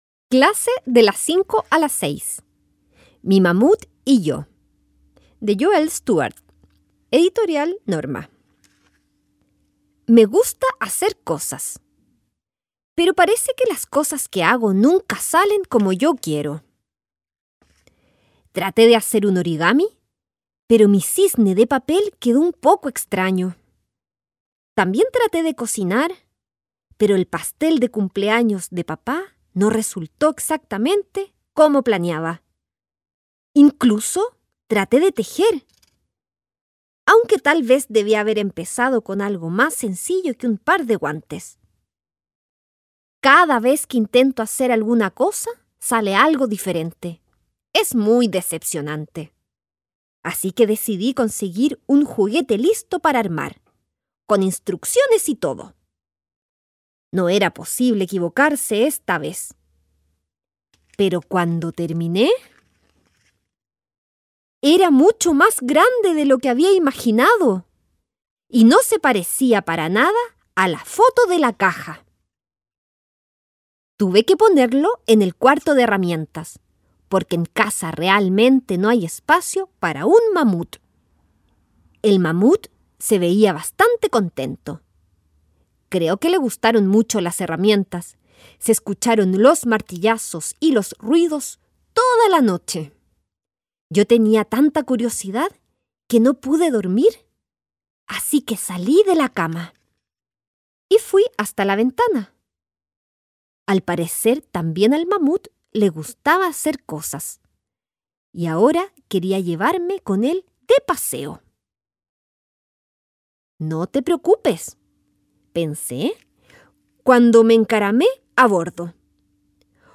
Audiolibro: Mi mamut y yo
Tipo: Audiolibros
audiotexto